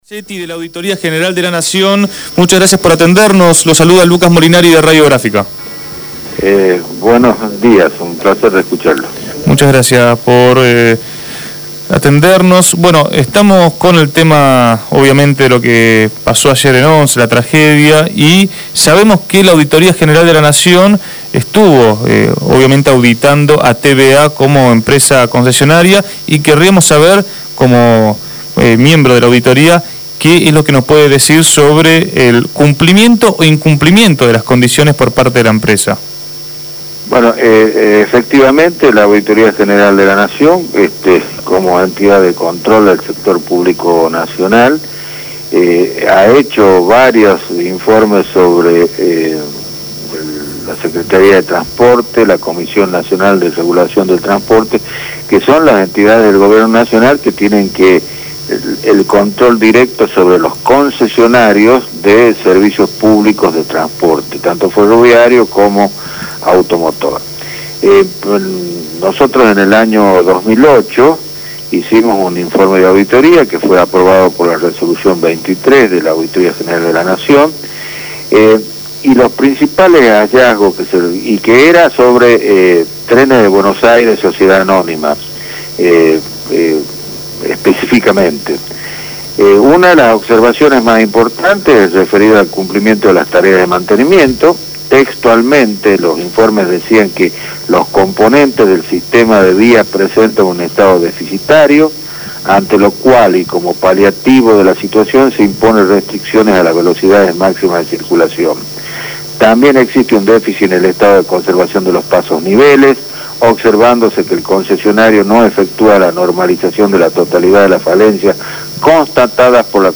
El Dr. Horacio Pernasetti, miembro de la Auditoría General de la nación, habló con Desde el barrio.